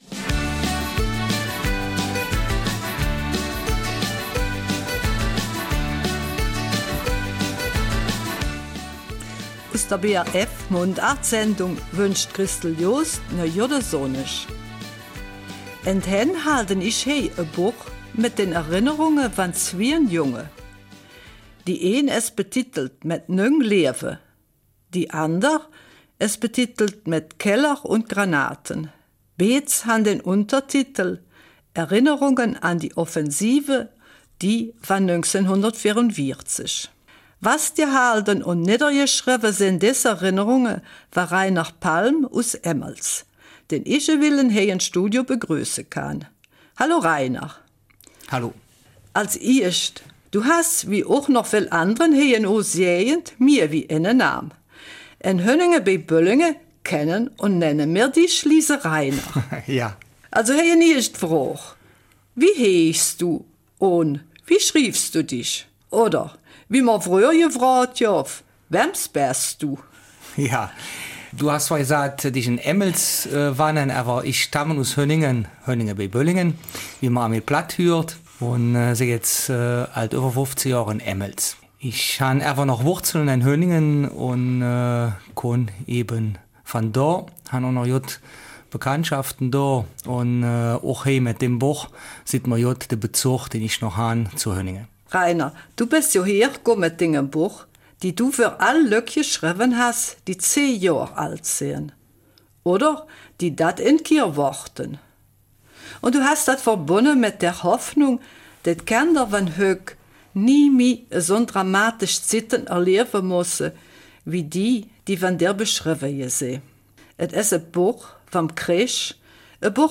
Eifeler Mundart - 8.
Mundartsendung